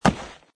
woodgrass2.mp3